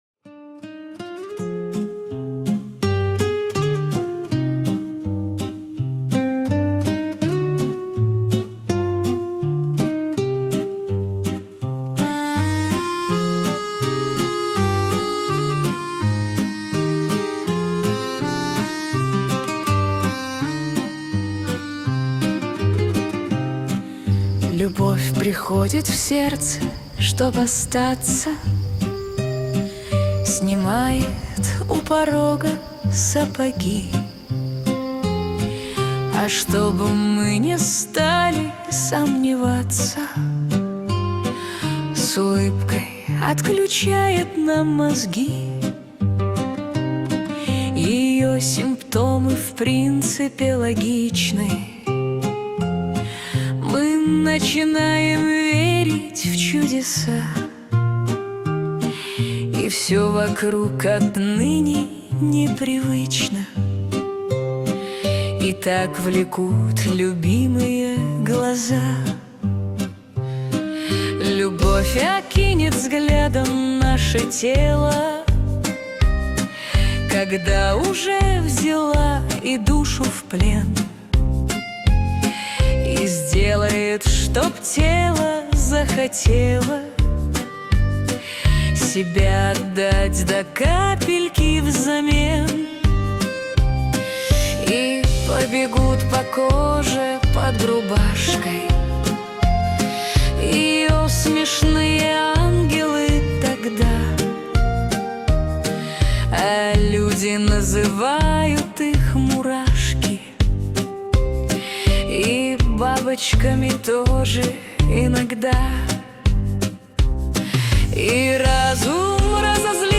Суно ИИ песня